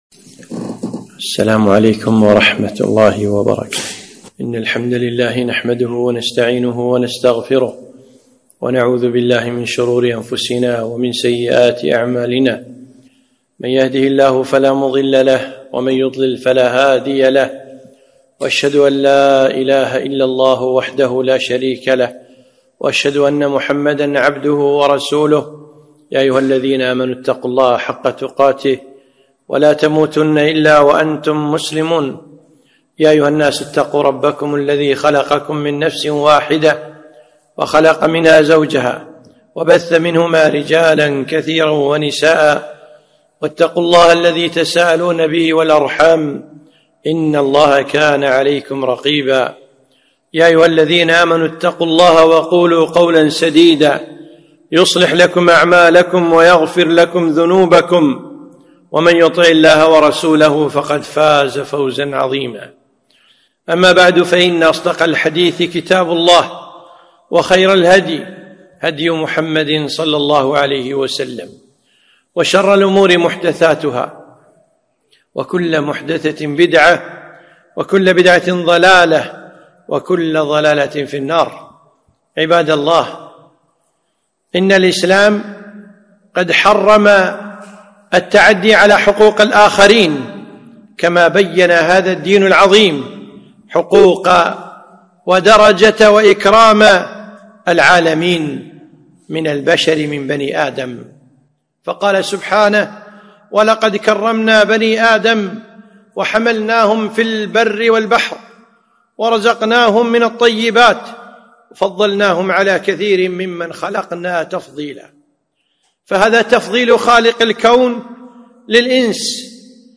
خطبة - حقوق البشر